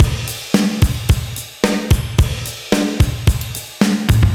AM_GateDrums_110-03.wav